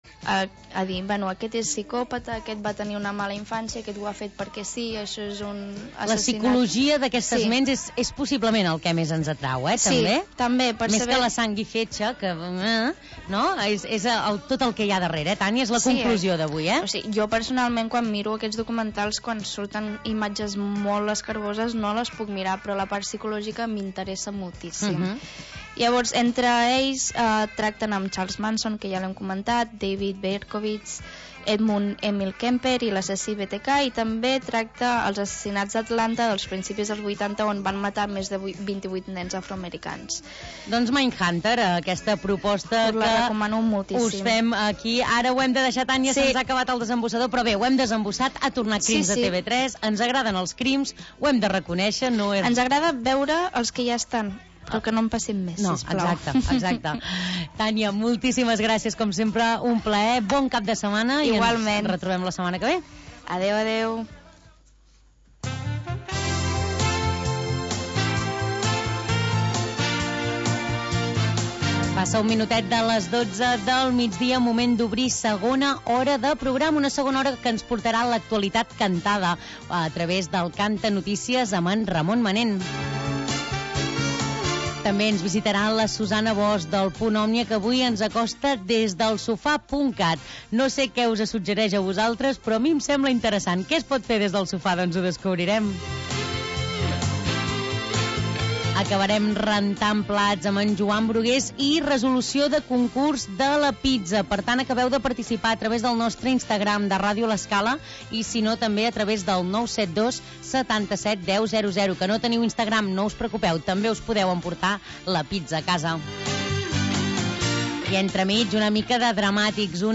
Magazín local d'entreteniment